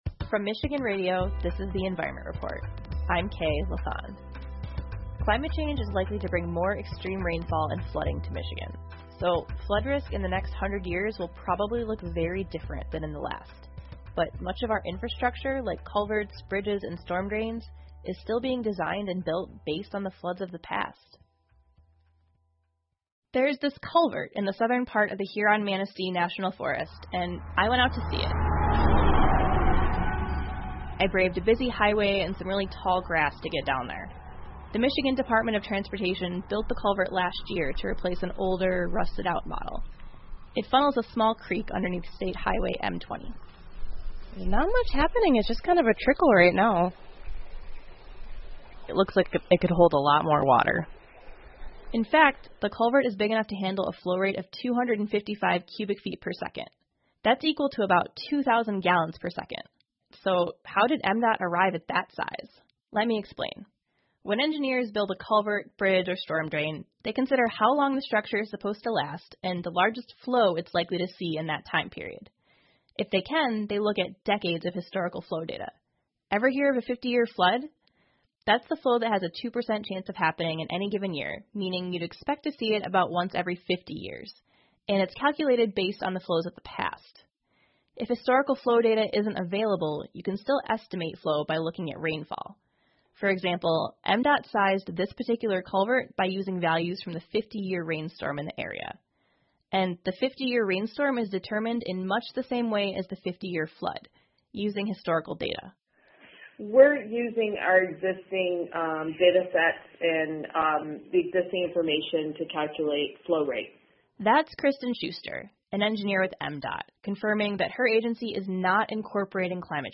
密歇根新闻广播 密西根州洪水 听力文件下载—在线英语听力室